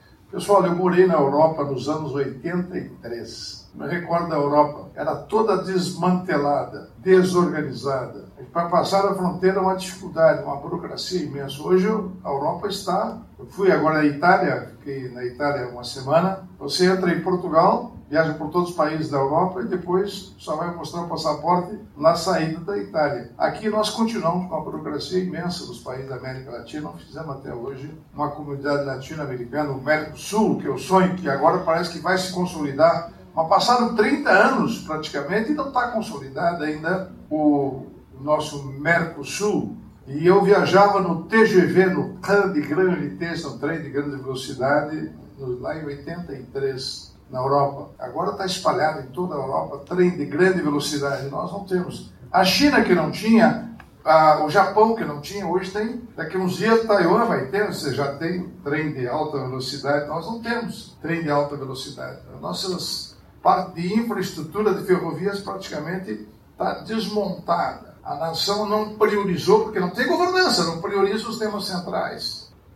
01-Ministro-prog-radio-sem-governanca-nacao-nao-prioriza-temas-centrais.mp3